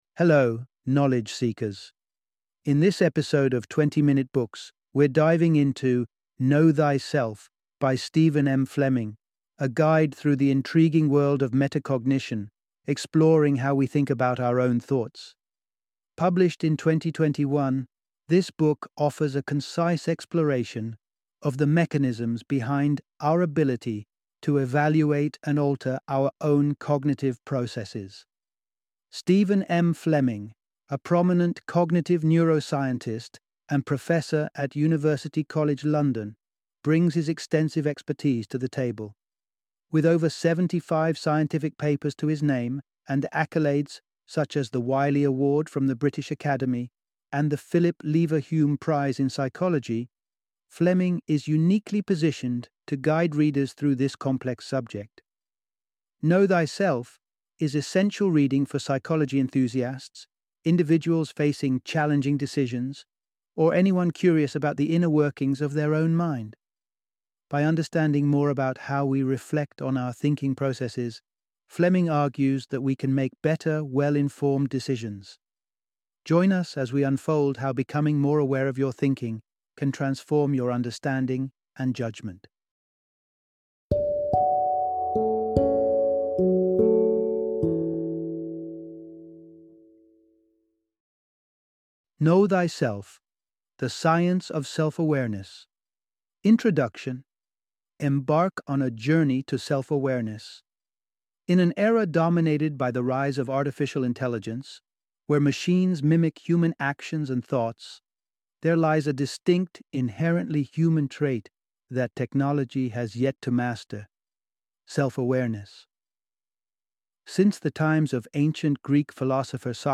Know Thyself - Audiobook Summary